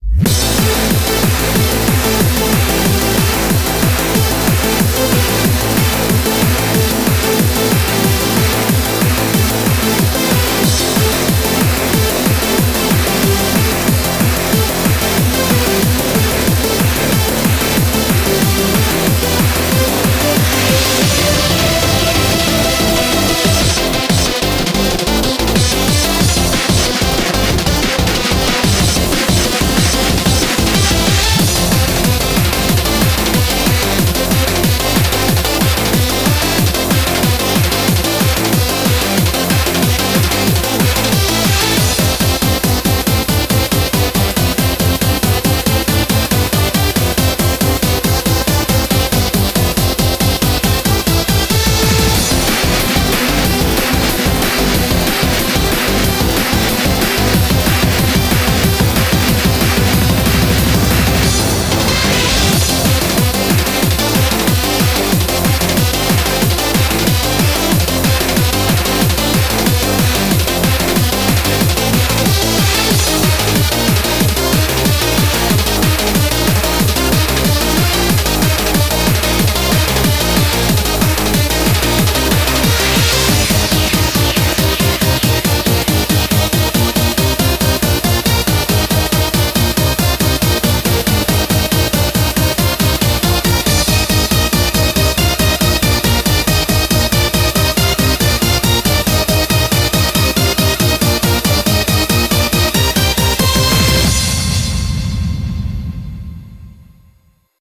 BPM46-278
MP3 QualityMusic Cut